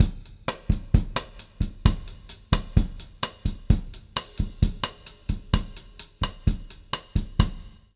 130BOSSA03-R.wav